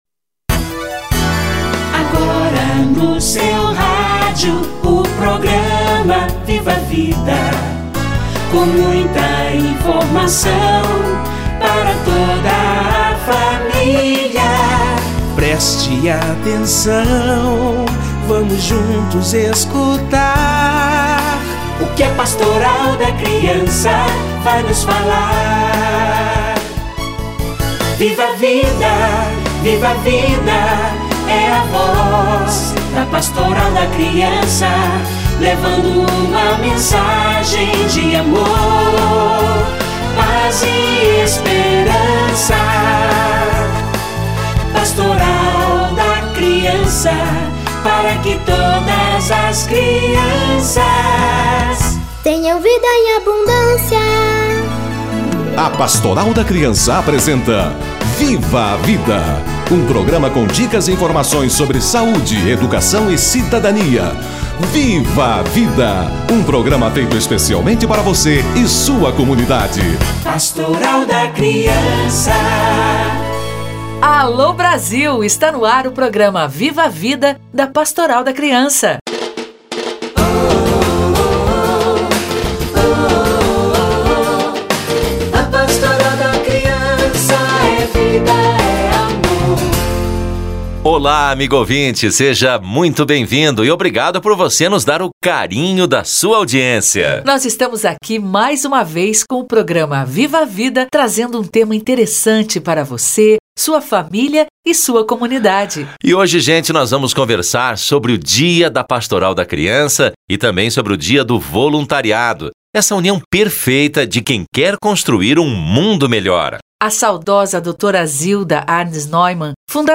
Ano da Paz / Dia da Pastoral da Criança - Entrevista